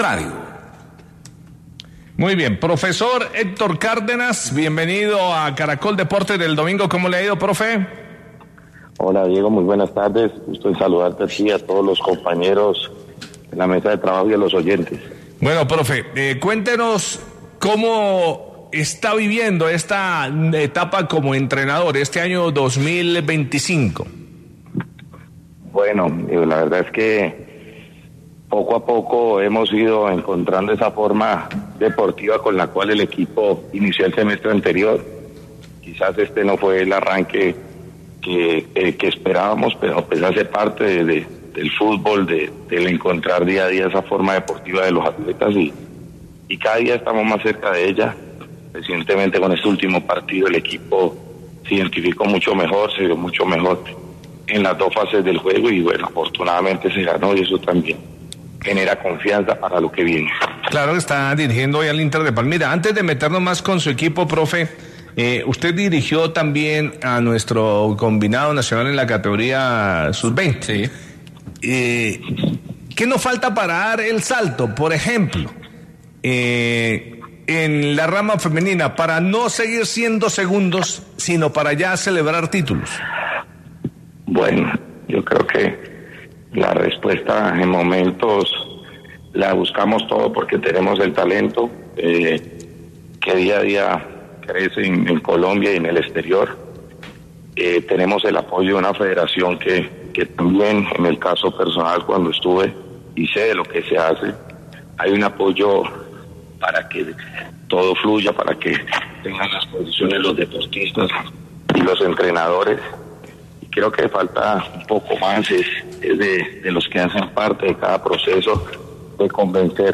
El exentrenador de la Selección Colombia Sub 20 habla sobre los procesos de todas las categorías.